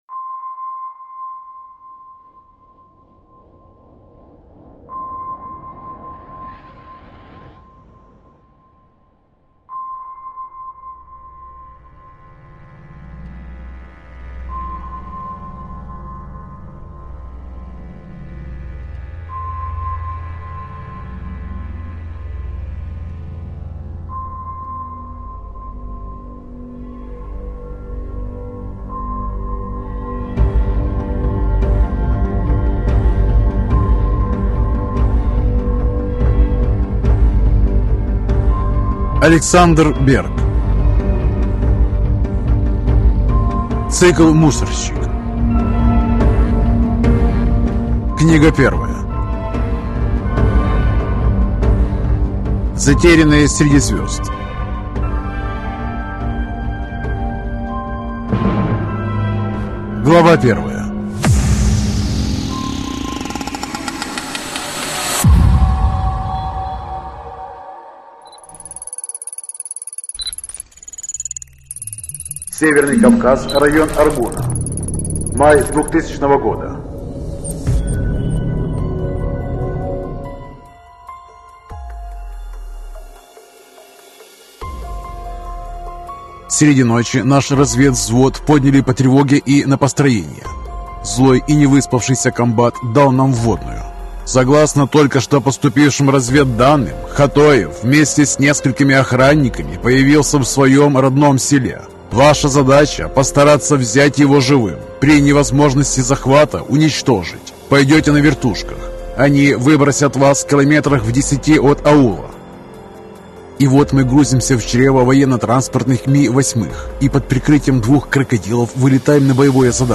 Аудиокнига Затерянные среди звёзд | Библиотека аудиокниг